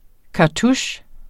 Udtale [ kɑˈtuɕ ]